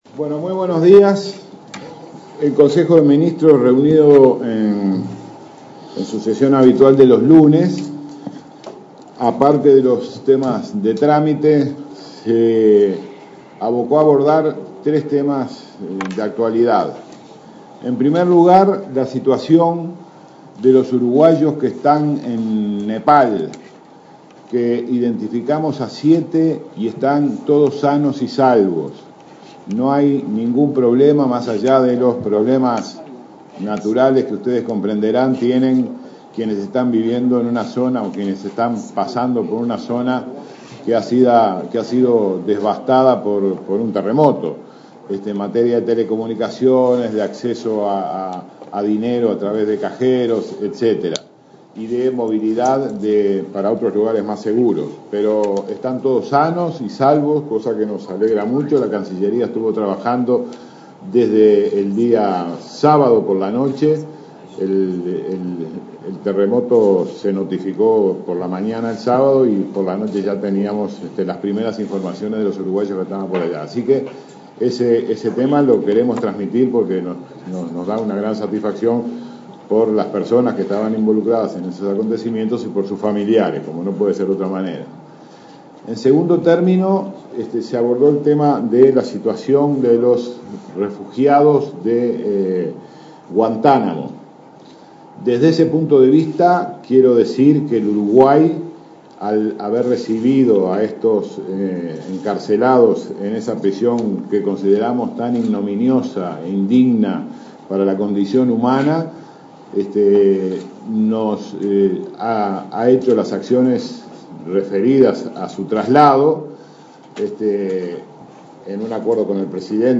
El canciller Rodolfo Nin Novoa se dirigió a los medios a la salida del consejo, en el que se trató la situación de los expresos de Guantánamo.